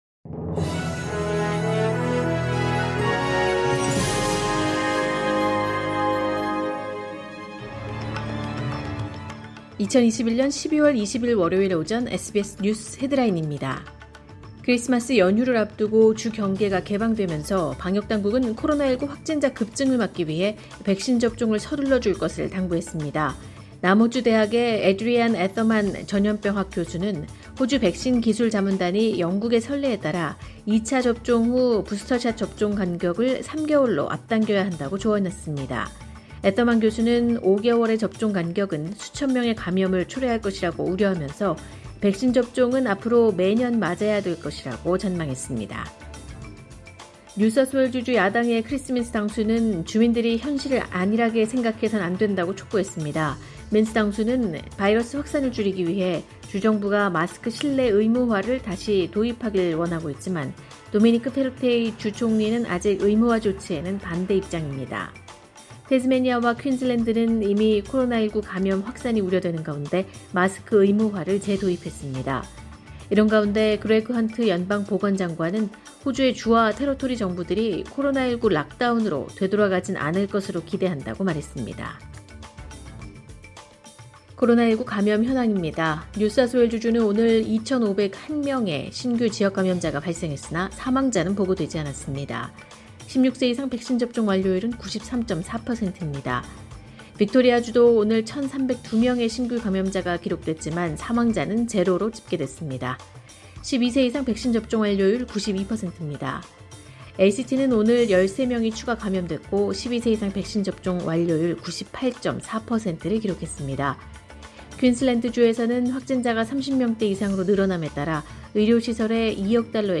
2021년 12월 20일 월요일 오전의 SBS 뉴스 헤드라인입니다.